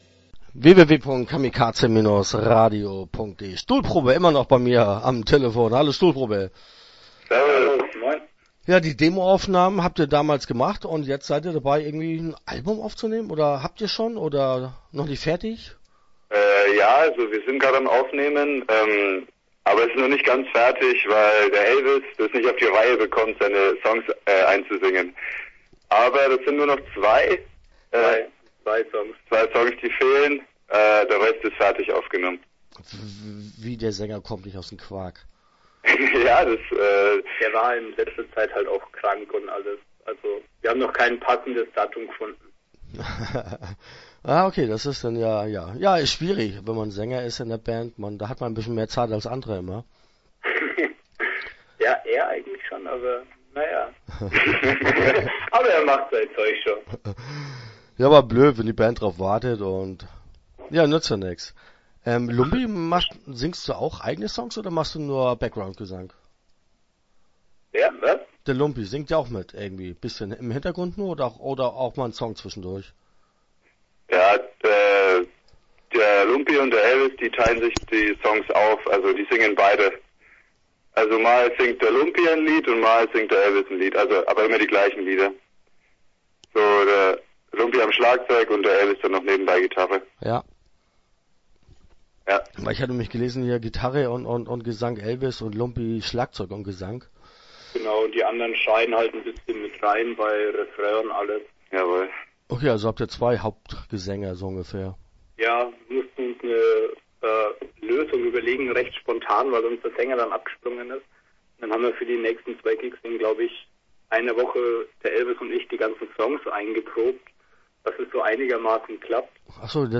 StuhlprobÄ - Interview Teil 1 (9:40)